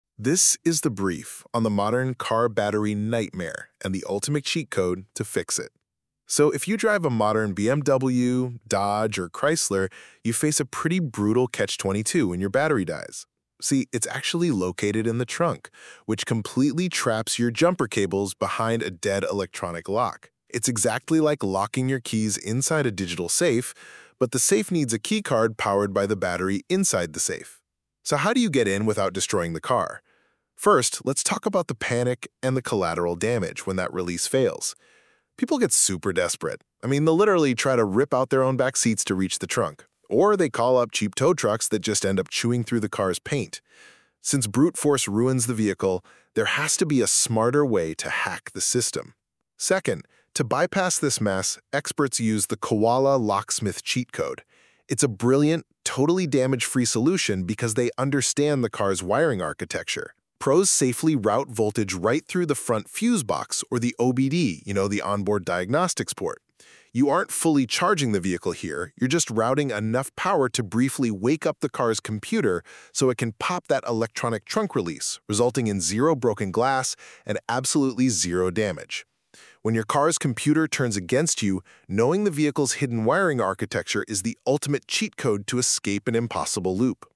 Short narrated guides on car key scams, emergency lockouts, luxury programming, and Florida-specific gotchas.